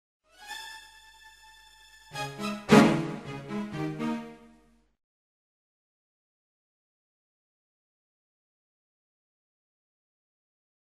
Colonne sonore che fanno parte del gioco.